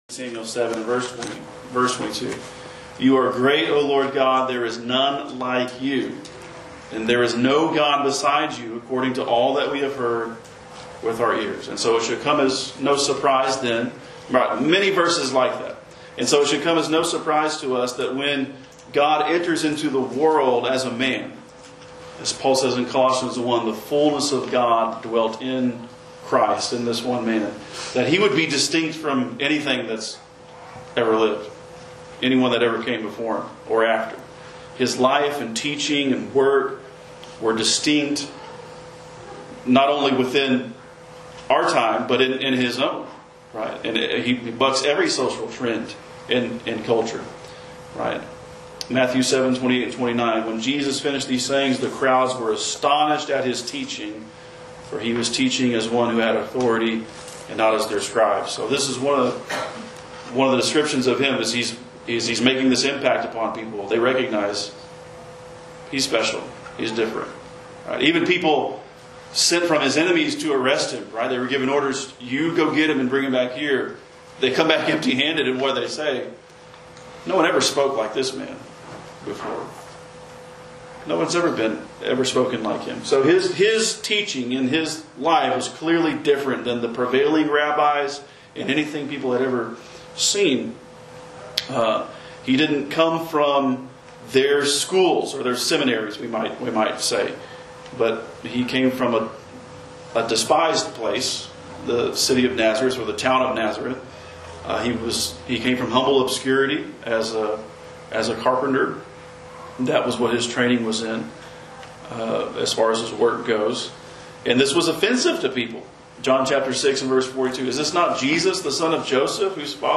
The Distinctive Nature of The Church- Gospel meeting